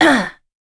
Morrah-Vox_Landing_b.wav